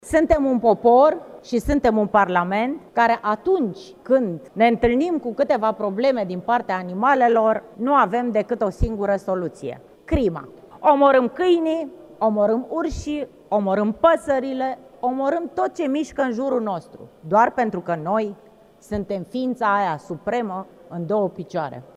Senatoarea PACE – Întâi România, Nadia Cerva, s-a declarat împotriva propunerii legislative